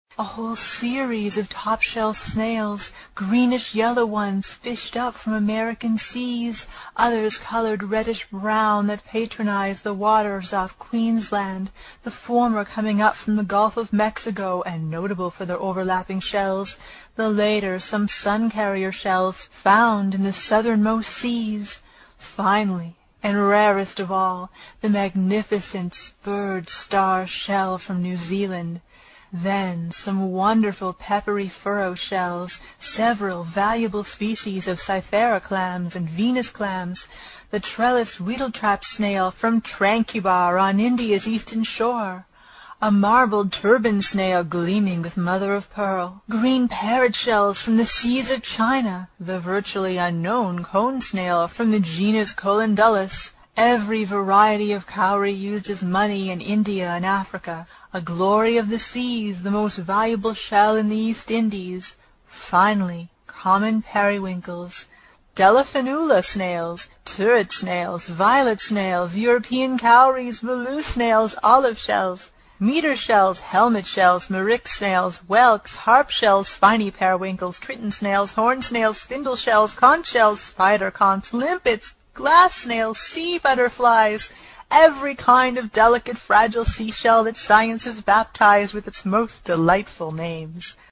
英语听书《海底两万里》第163期 第11章 诺第留斯号(14) 听力文件下载—在线英语听力室
在线英语听力室英语听书《海底两万里》第163期 第11章 诺第留斯号(14)的听力文件下载,《海底两万里》中英双语有声读物附MP3下载